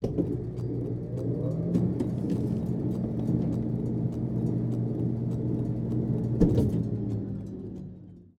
scpcb-godot/SFX/Ambient/Pre-breach/Ambient2.ogg at master